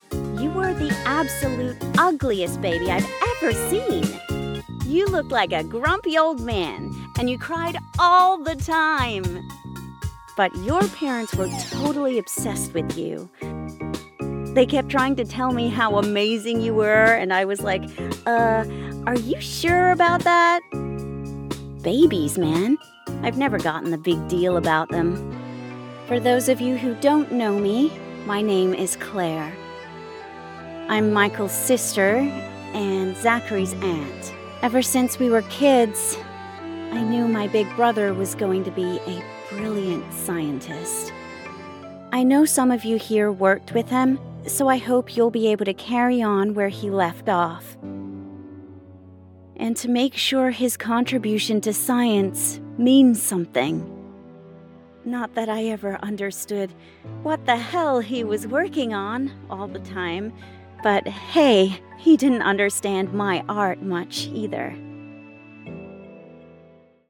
INTERACTIVE-VisualNovel_ElegiesAYA_Claire-F-US-Adult_Sardonic-Genuine.mp3